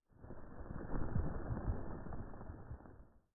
Minecraft Version Minecraft Version 1.21.5 Latest Release | Latest Snapshot 1.21.5 / assets / minecraft / sounds / ambient / nether / nether_wastes / ground2.ogg Compare With Compare With Latest Release | Latest Snapshot